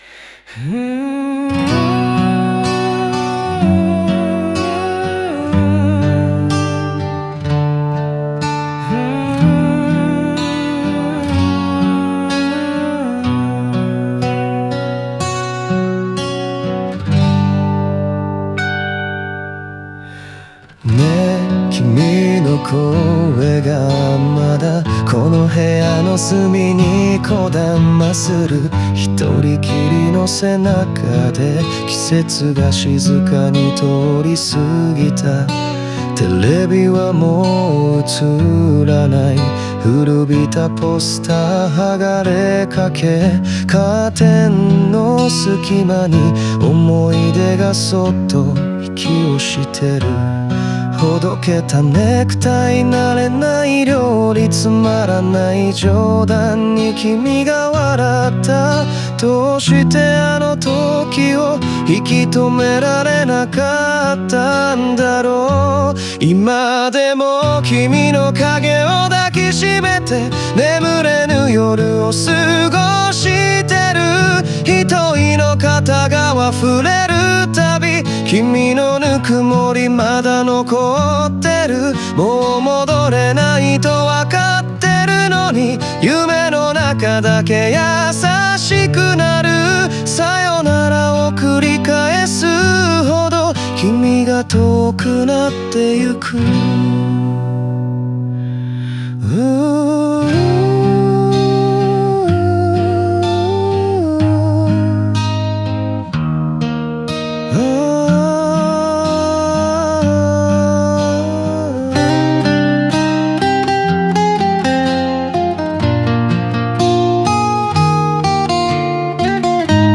オリジナル曲♪
愛を守れなかった悔しさと、自分を責め続ける弱さが、淡々としたメロディに乗せて切なく響きます。